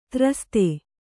♪ traste